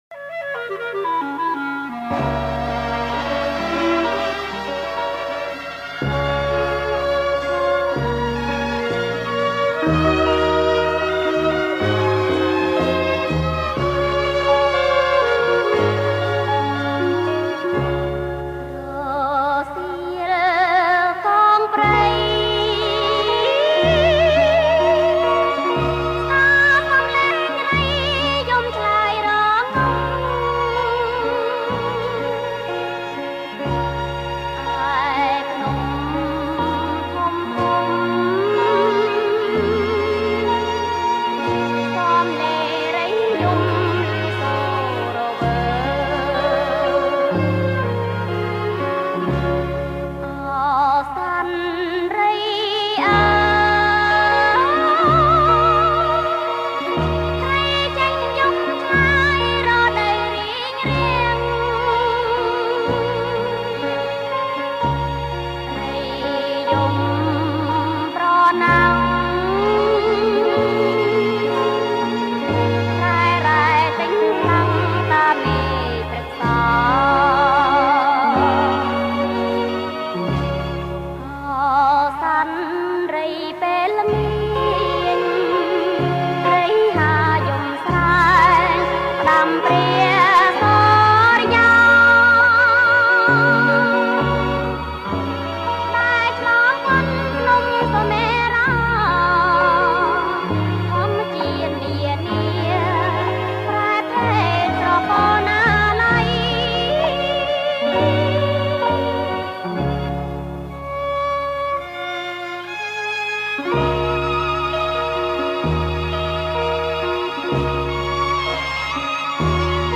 ប្រគំជាចង្វាក់ Slow Folk